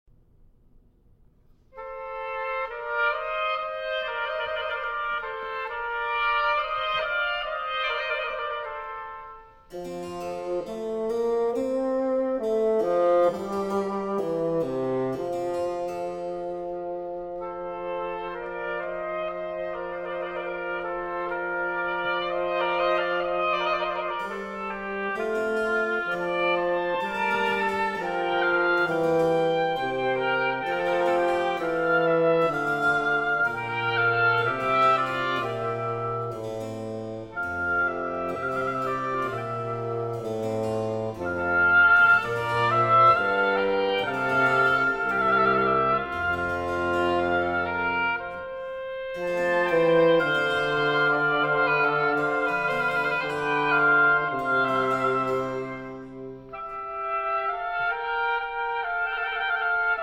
Oboe
Bassoon
Harpsichord
from Trio Sonata in F Major